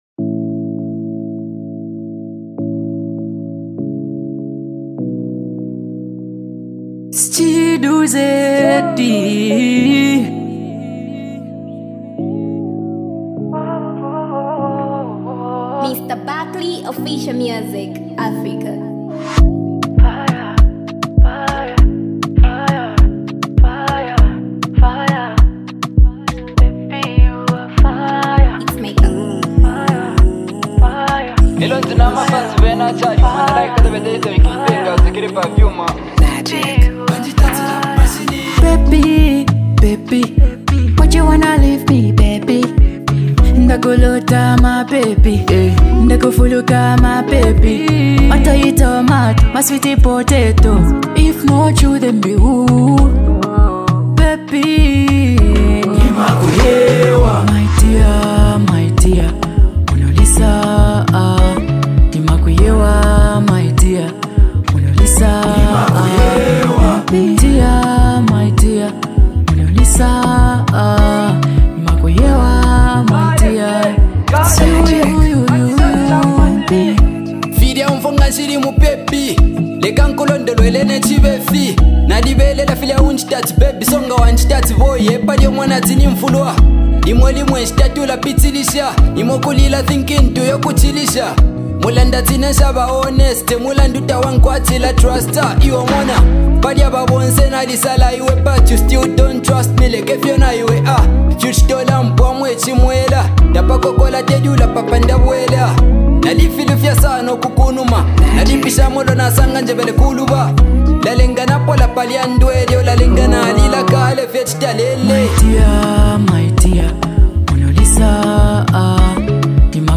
heartfelt love song